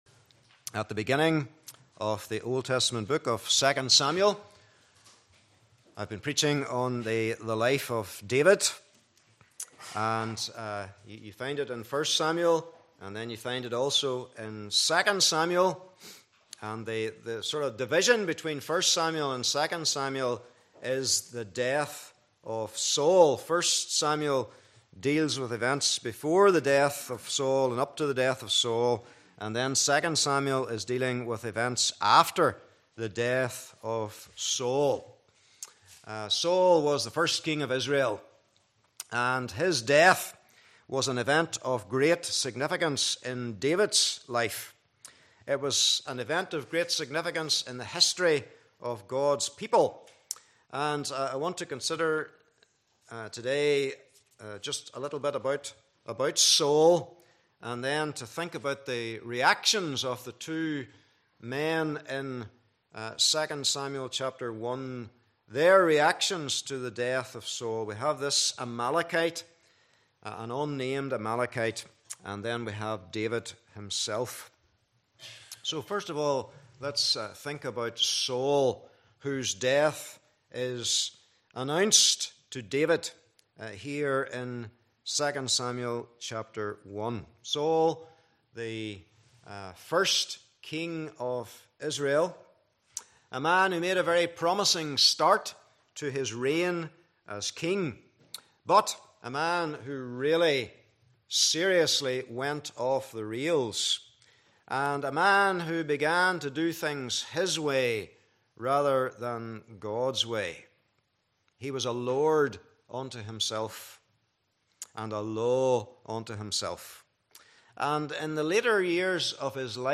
Passage: 2 Samuel 1:1-27 Service Type: Morning Service